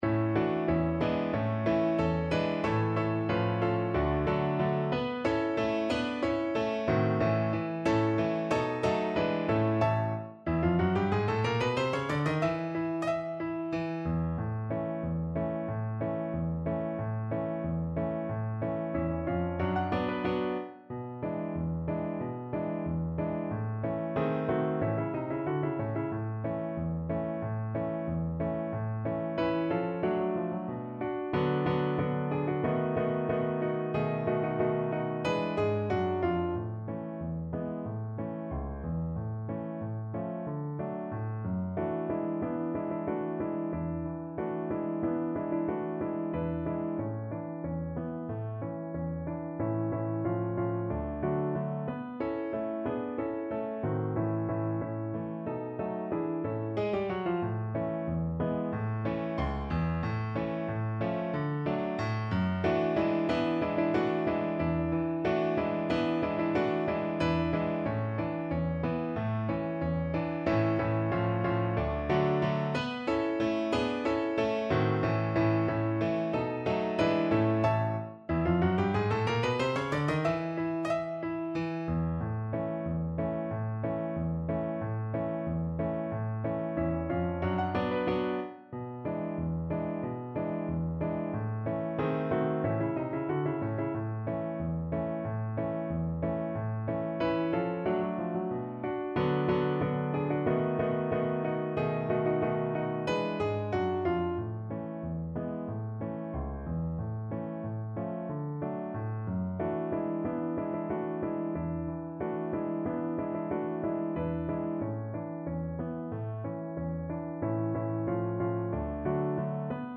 Allegretto =92
2/4 (View more 2/4 Music)